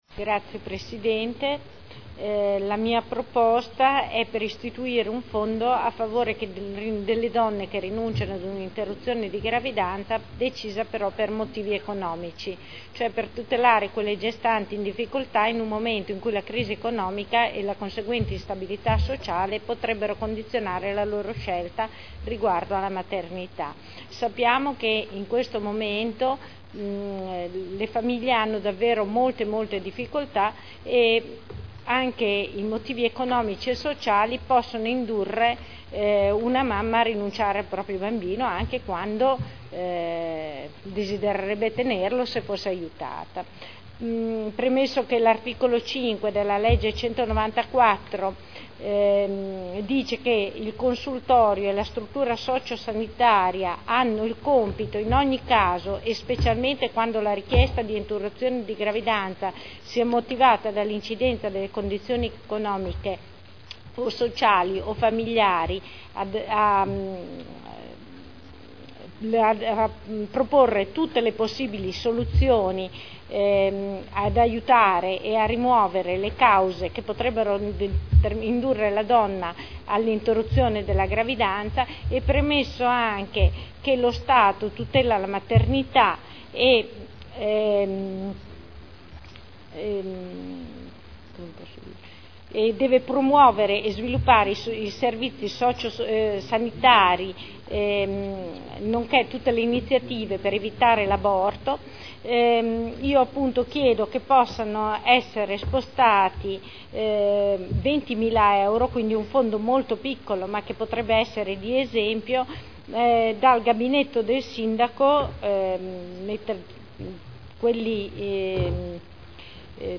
Luigia Santoro — Sito Audio Consiglio Comunale